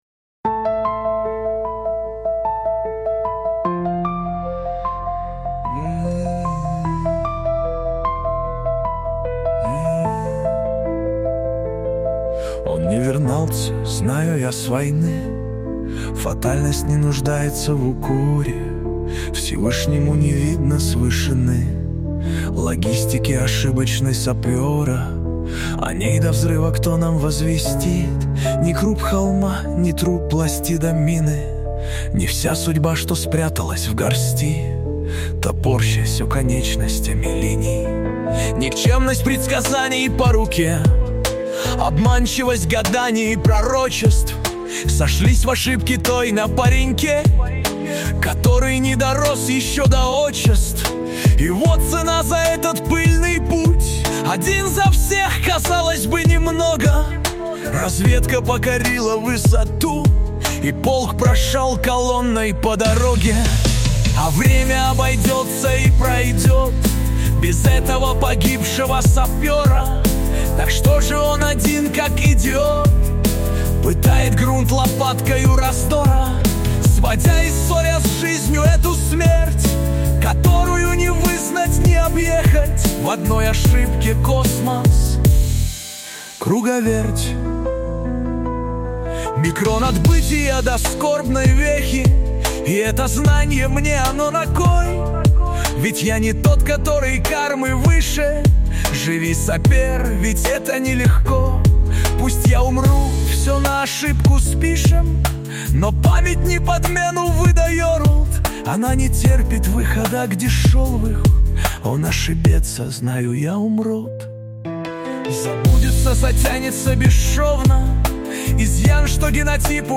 saper.mp3 (2867k) Попытка песни ИИ